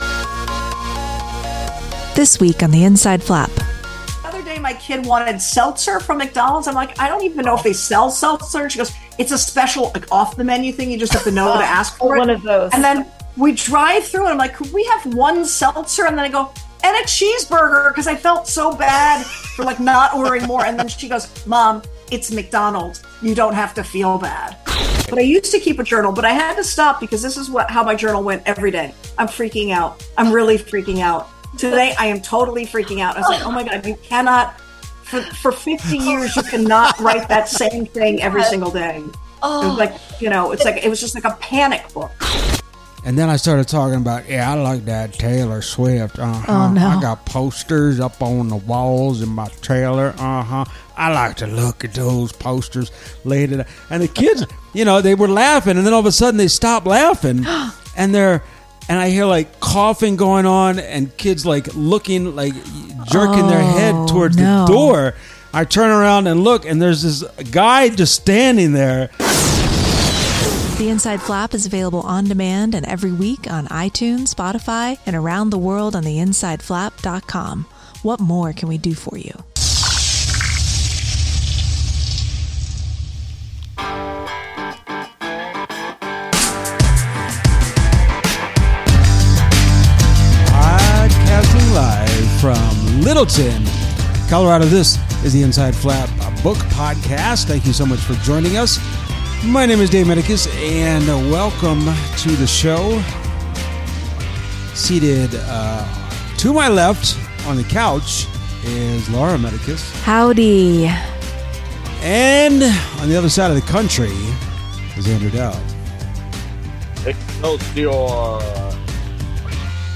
A.M. Homes joins us for a fun chat about her new novel The Unfolding, secret fast food menu items, how reality has eclipsed fiction, election day orange cruller donuts, regional candies, and the reason why she doesn’t keep a private notebook.